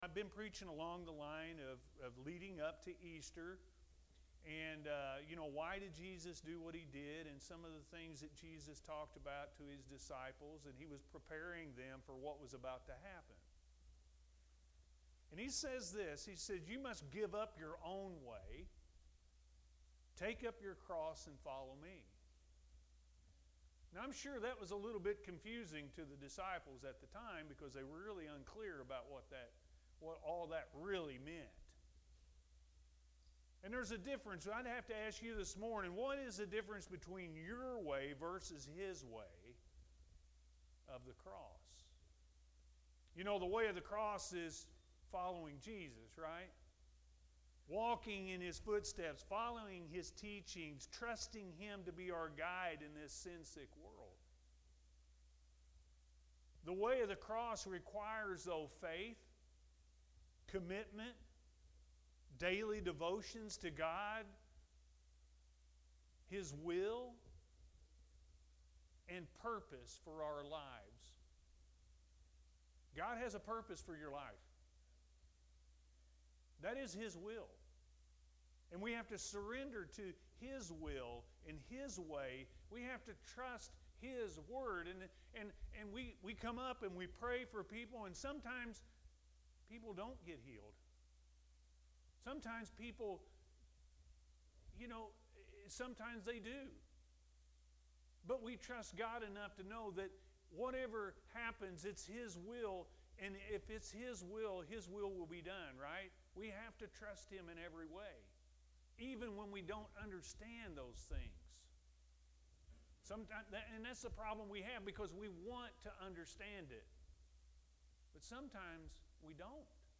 The Way Of The Cross-A.M. Service – Anna First Church of the Nazarene